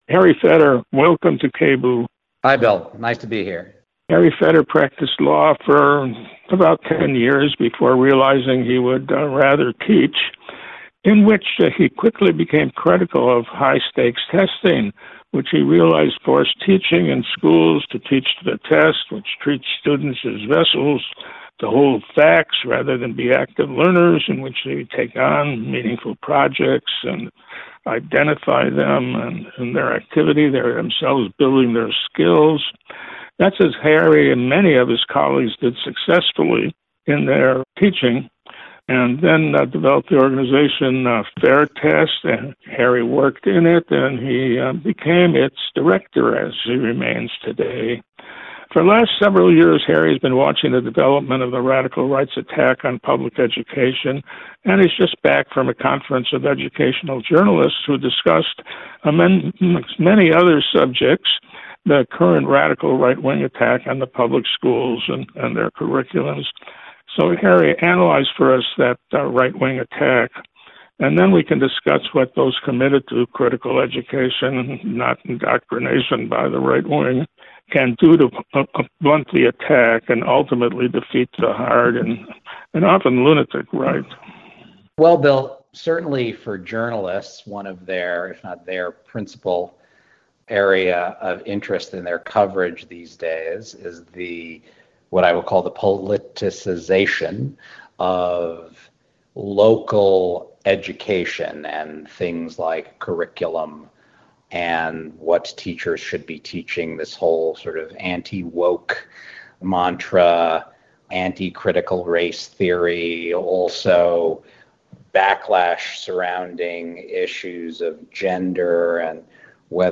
In part two of a two part interview